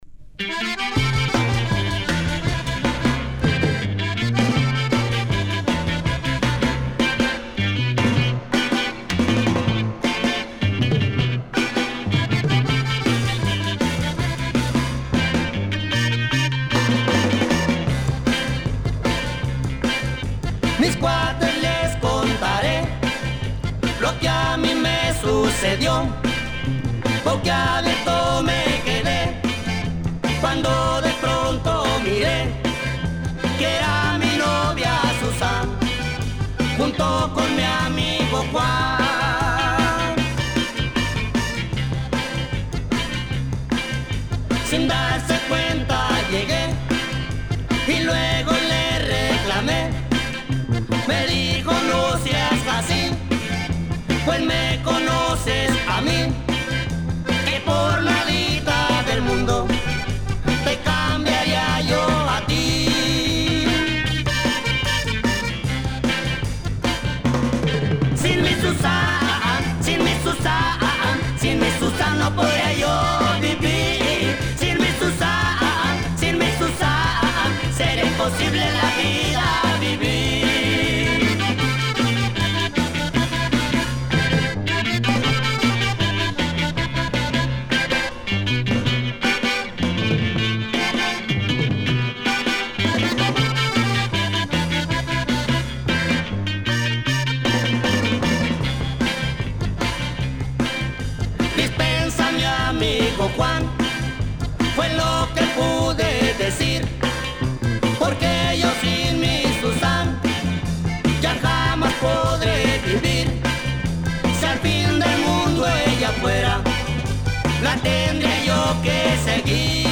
Published January 3, 2010 Garage/Rock 12 Comments
Even with their straight forward rock break-beat